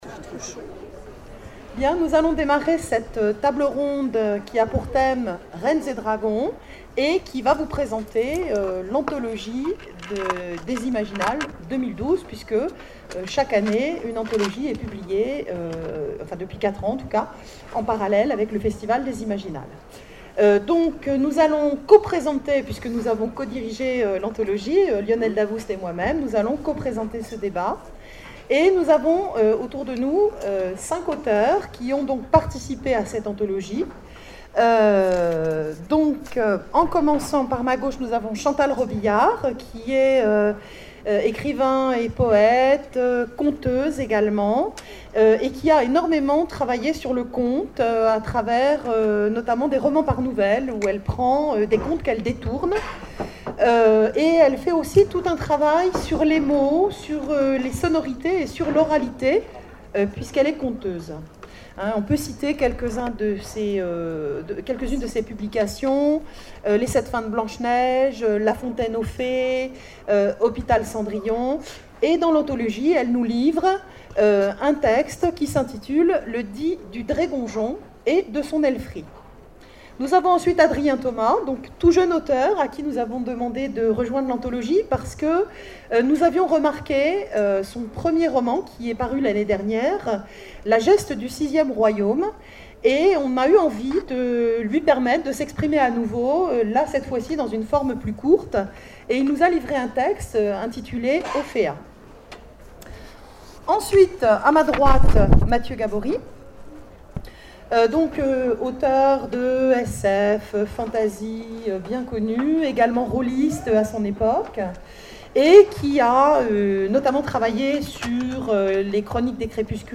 Imaginales 2012 : Conférence Reines et Dragons, l'anthologie du festival